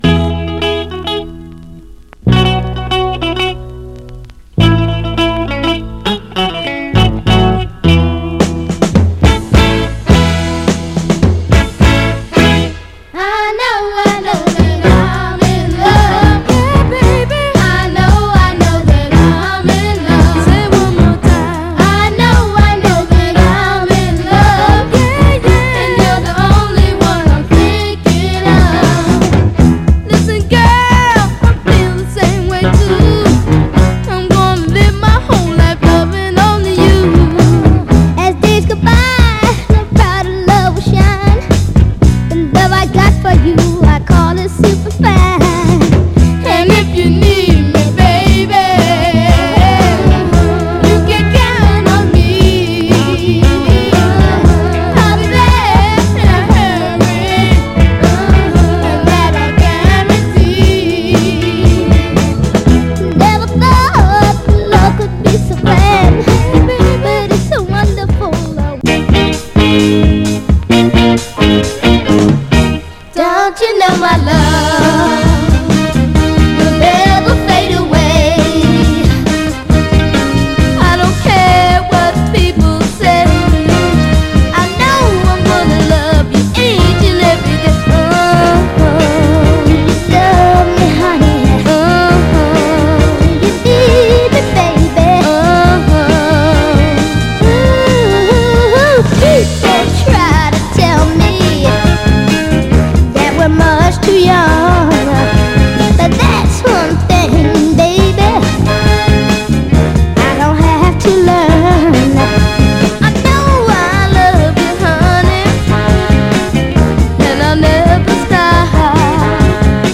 所々キズ箇所でチリつきますが、大きく目立つノイズは少なく、見た目の割にプレイ概ね良好です。
※試聴音源は実際にお送りする商品から録音したものです※